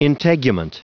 Prononciation du mot integument en anglais (fichier audio)
Prononciation du mot : integument